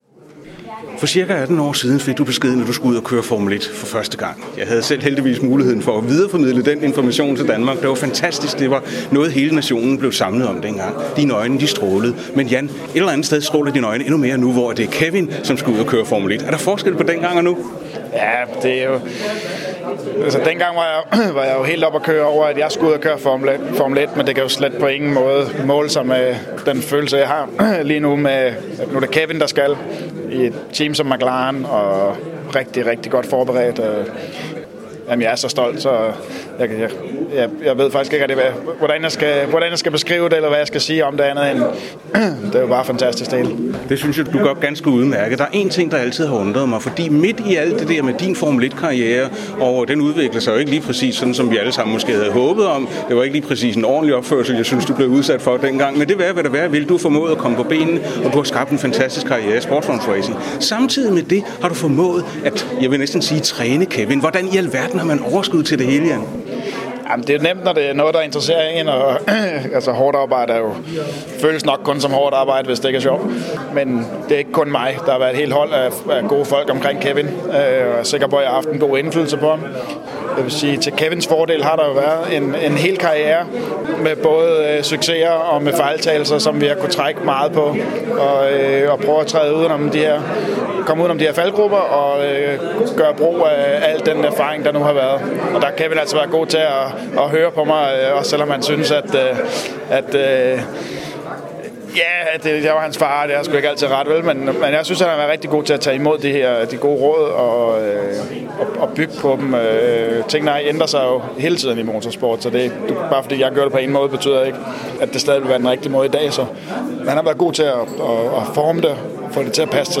Hør interview'et med Jan Magnussen om Kevins Formel 1 kontrakt, muligheder og Jans egen fremtid i sportsvognsracing
2013 Jan Magnussen interview - Kevin i F1.mp3